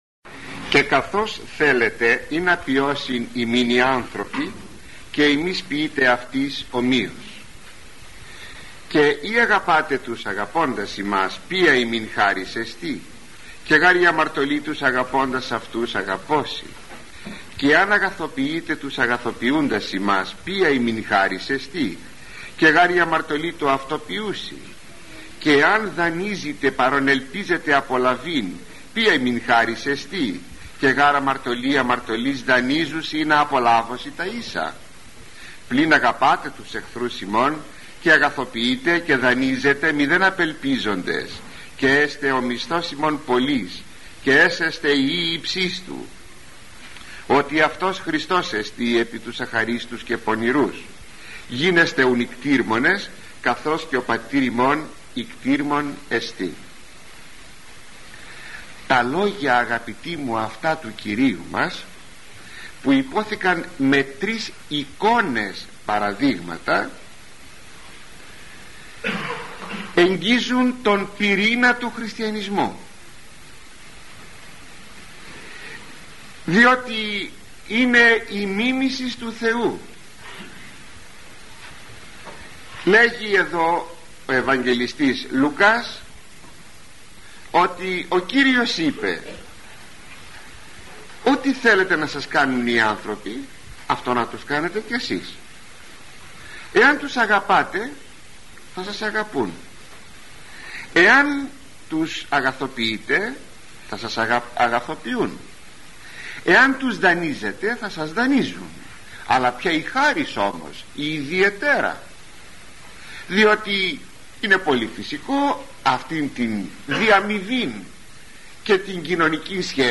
Κυριακή Β. Λουκά – ηχογραφημένη ομιλία